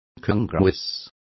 Complete with pronunciation of the translation of congruous.